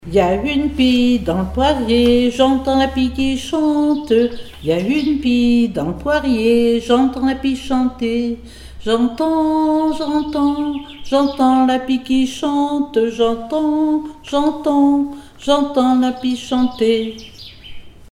Couplets à danser
enfantine : berceuse
Comptines et formulettes enfantines
Pièce musicale inédite